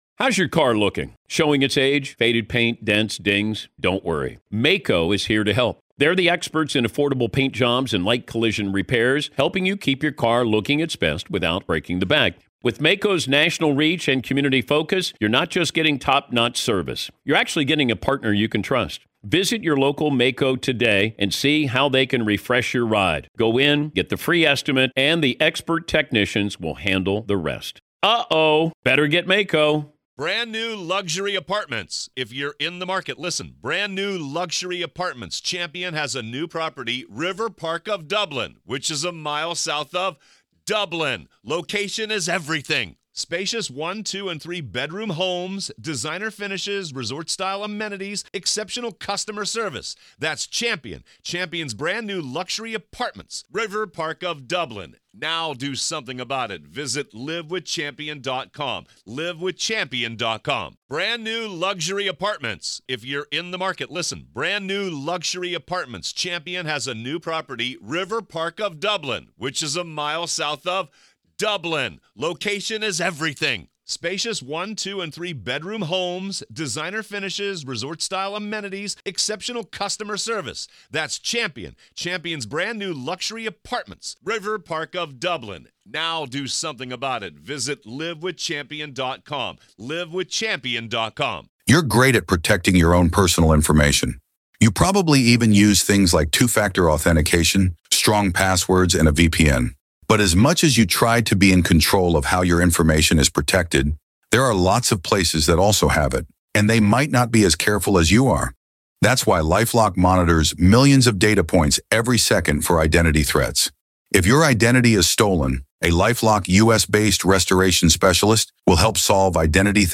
The judge in the Bryan Kohberger case has put a stricter gag order in place. Listen to hear the full reading of the gag order document.